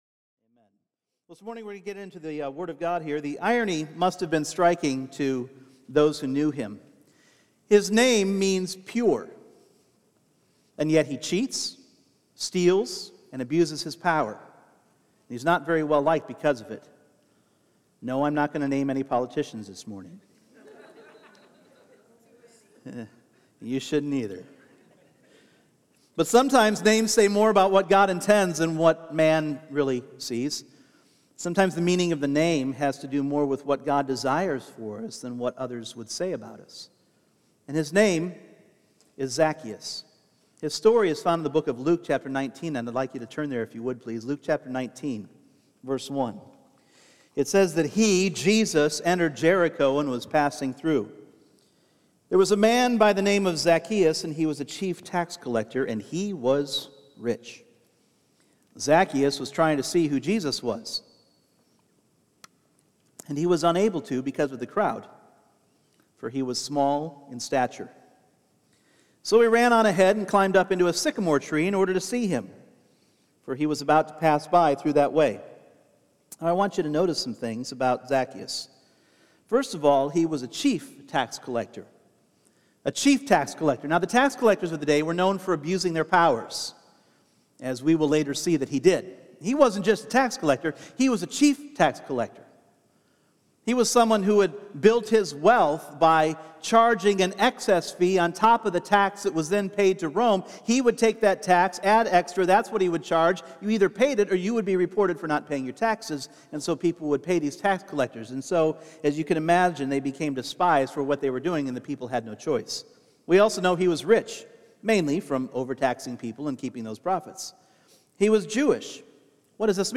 Individual Messages Service Type: Sunday Morning Can a corrupt man who has cheated and betrayed people ever possibly be transformed?